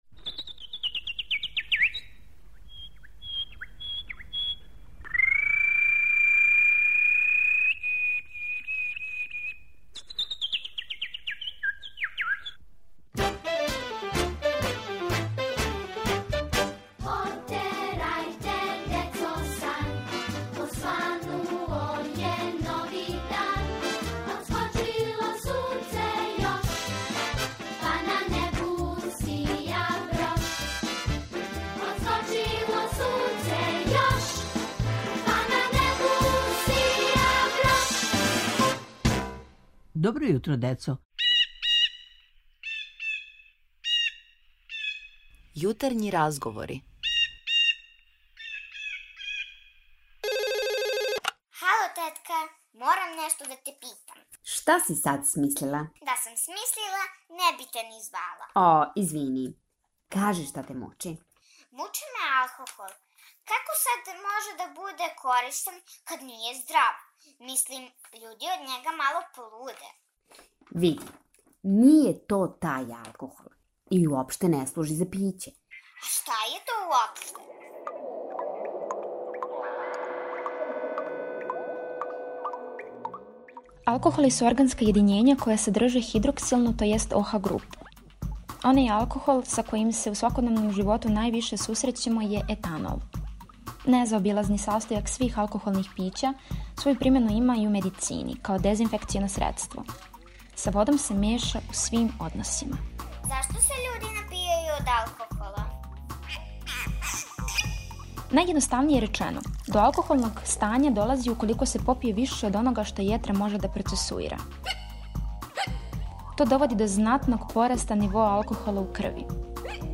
У серијалу "Јутарњи разговор" једна девојчица сазнаће све о алкохолу као средству за дезинфекцију. На сва питања одговориће наш стручњак, а ви слушајте пажљиво, да не бисмо нагађали!